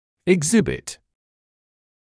[ɪg’zɪbɪt][иг’зибит]демострировать, показывать; проявлять; экспонат (на выставке)